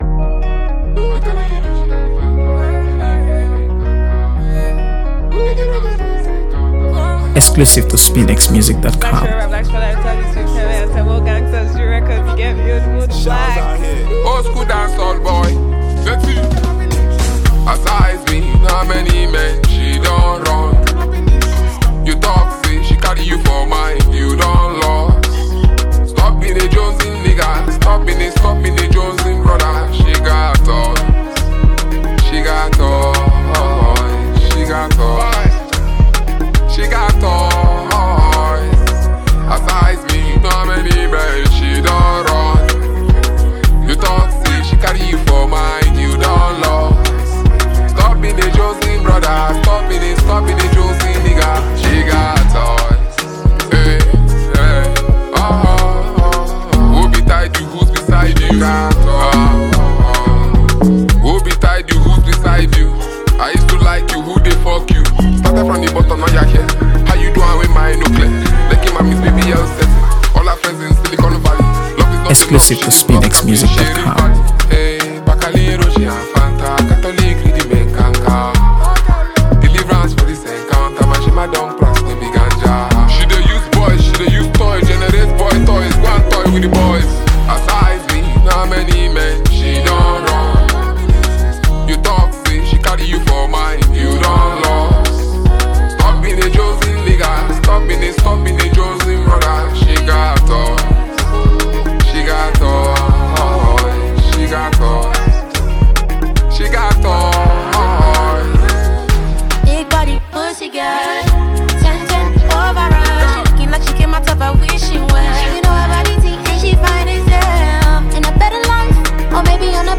AfroBeats | AfroBeats songs
signature energy and lyrical precision
lays down a bold and dynamic backdrop
commanding delivery